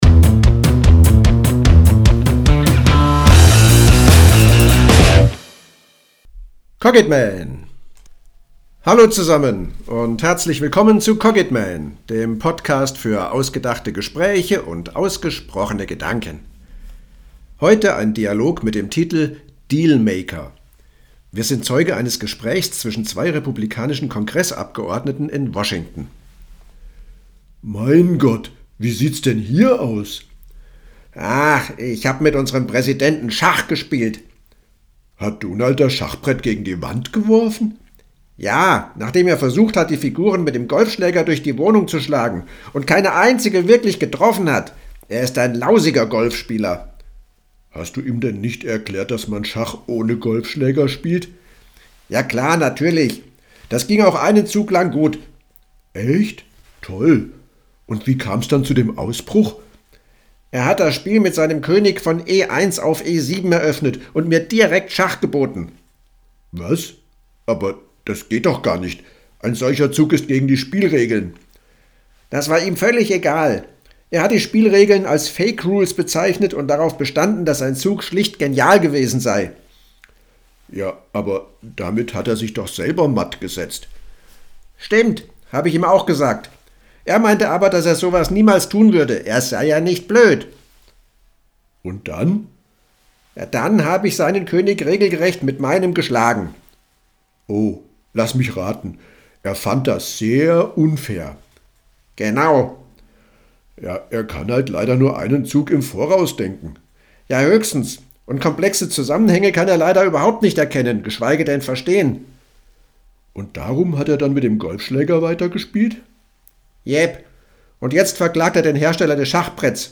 Dialog-Dealmaker.mp3